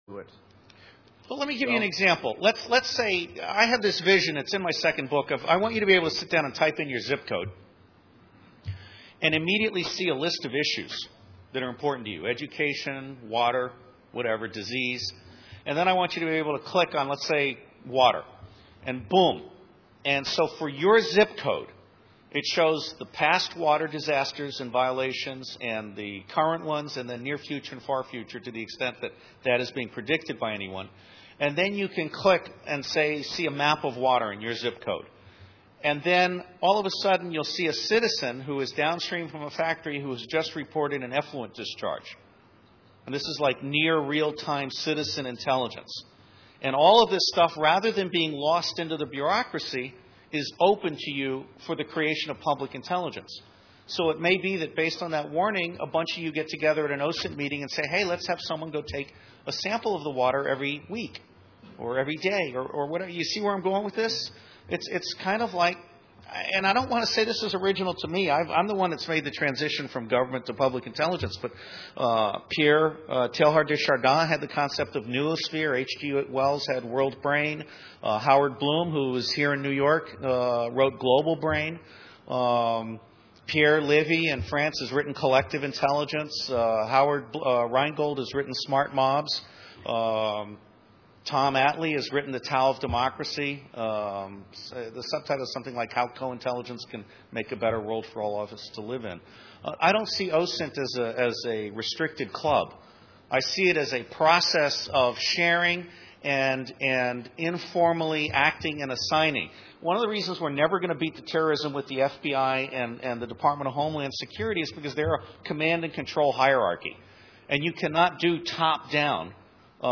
a speech